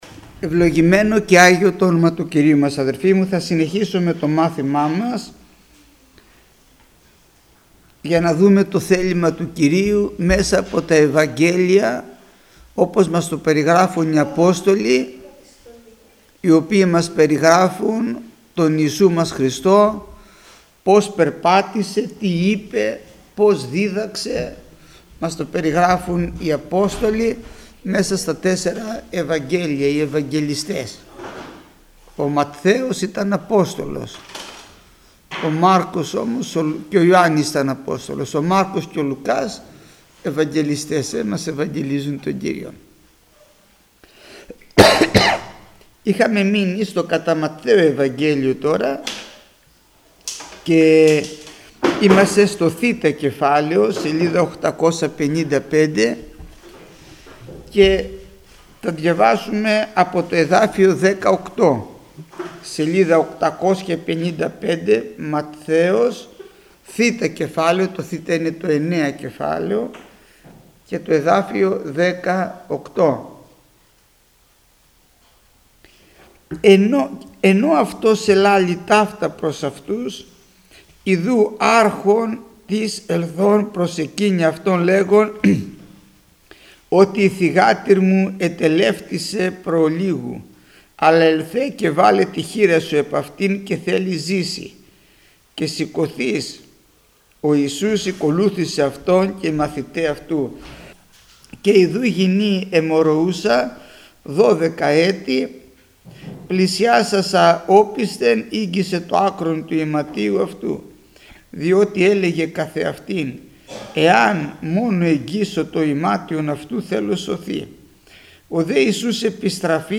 Μάθημα 406ο Γεννηθήτω το θέλημά σου